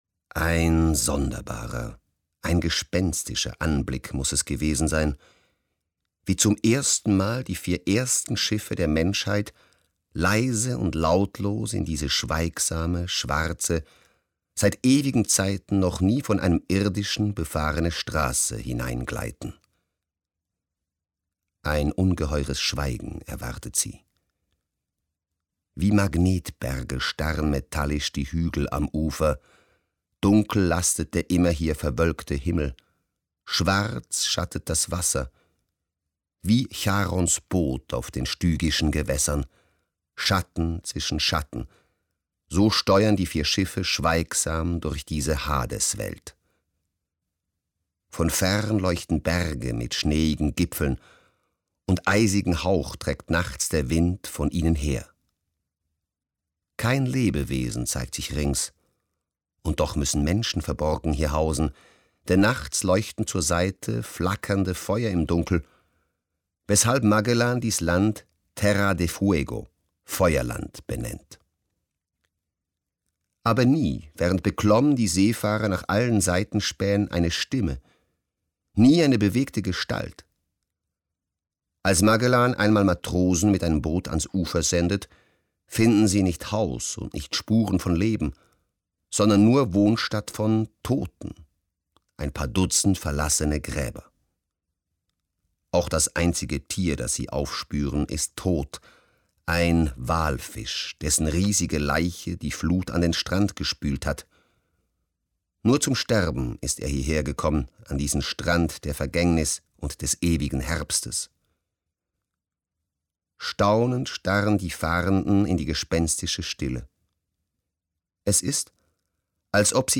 Stimmlage: tief; Stimmcharakter: kräftig, warm.